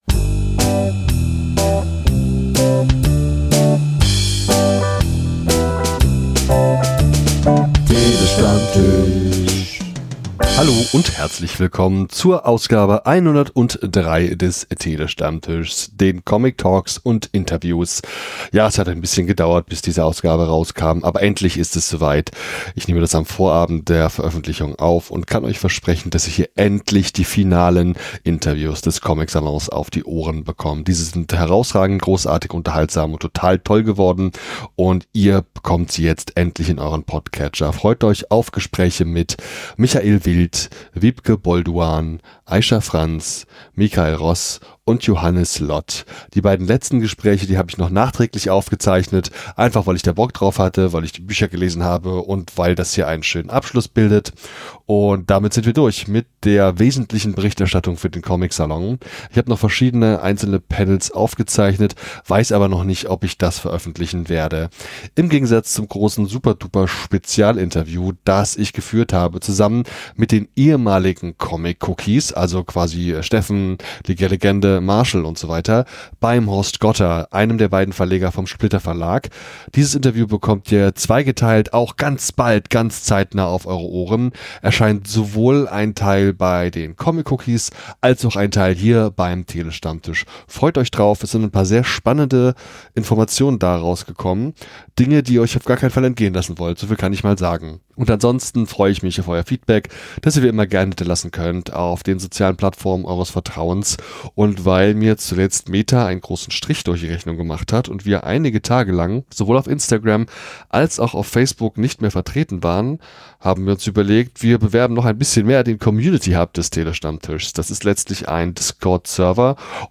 Ich habe vor Ort sehr viel gearbeitet und viele Stunden lang Interviews geführt und aufgezeichnet.